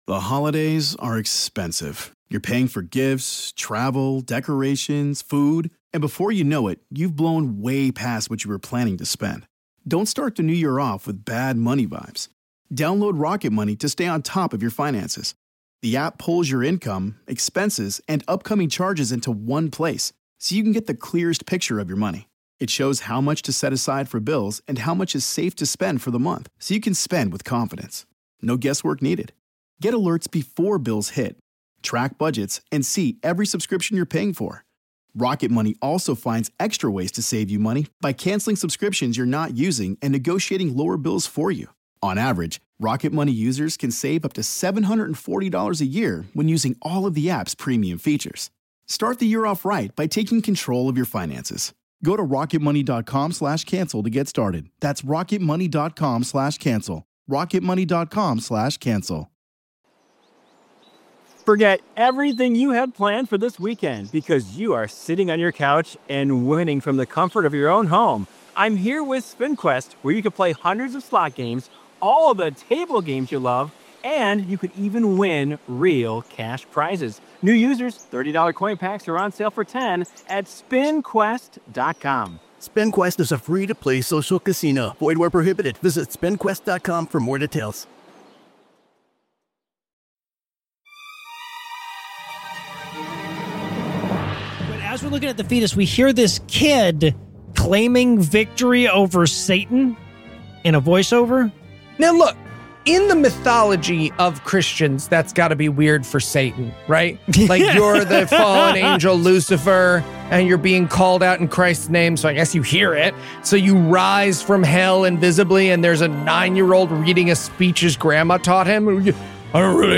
This week, the guys team up for an atheist review of Come Out in Jesus Name, Greg Locke's documentary about all the demons that make us do this show every week.